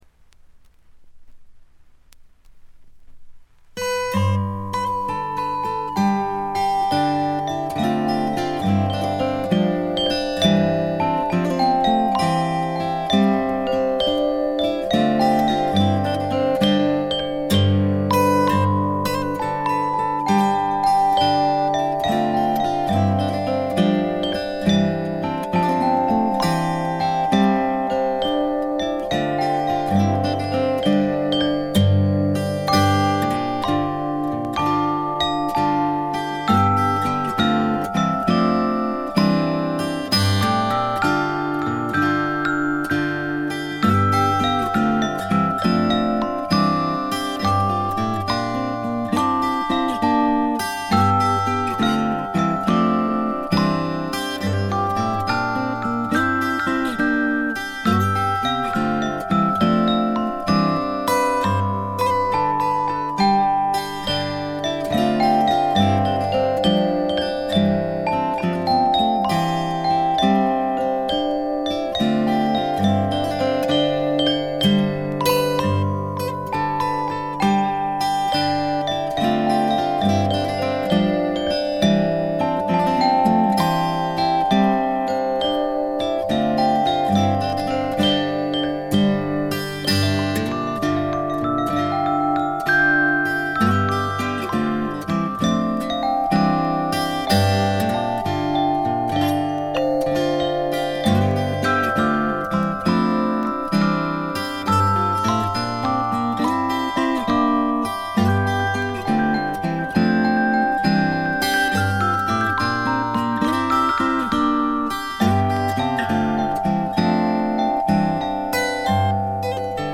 ごくわずかなノイズ感のみ。
試聴曲は現品からの取り込み音源です。
Recorded at studio Kamboui, Chatellerault, France.